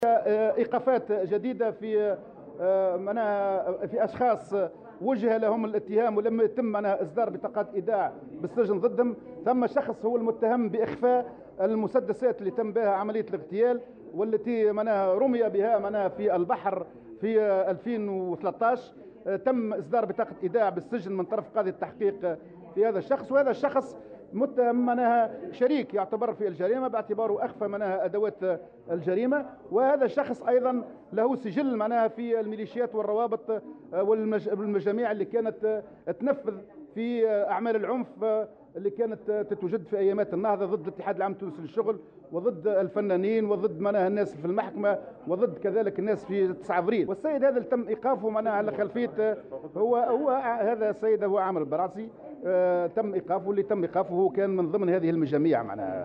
في تصريح اليوم لمراسلة "الجوهرة أف أم" على هامش ندوة صحفية نظمها التيار الشعبي